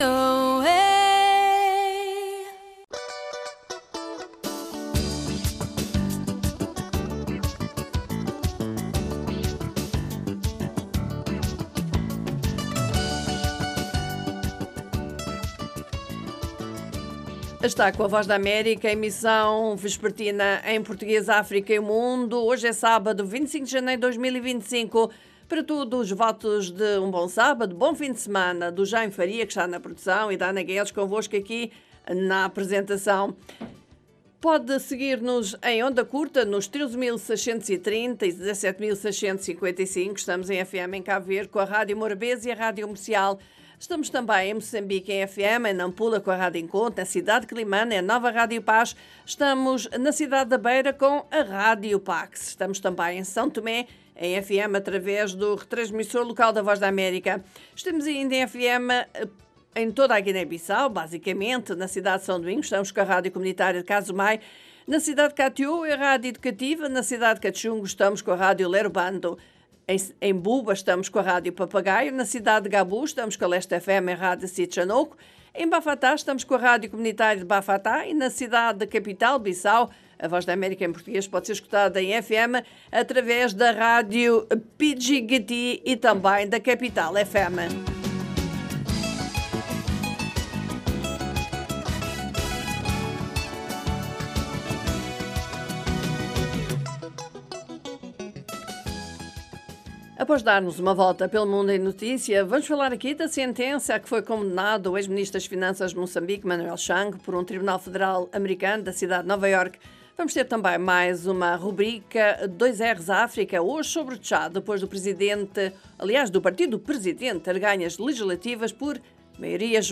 … continue reading 50 episodios # Notícias # Mesa Redonda # Voz Da América